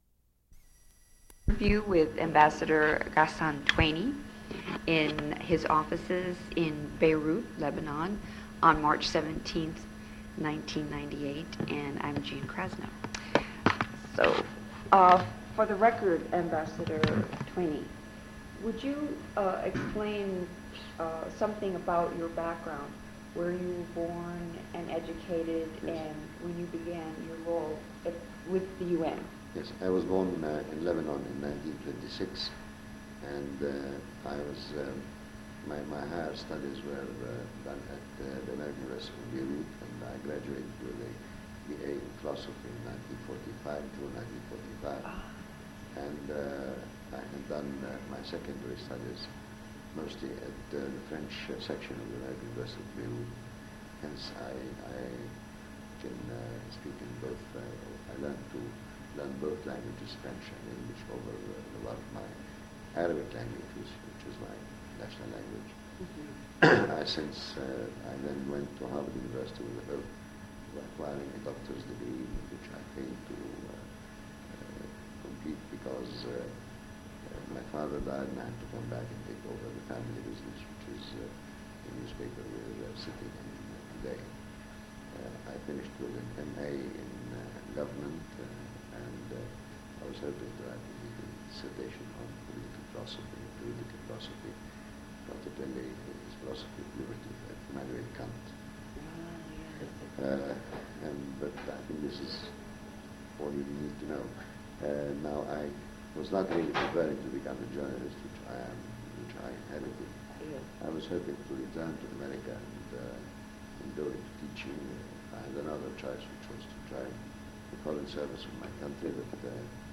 Interview with Ghassan Tueni /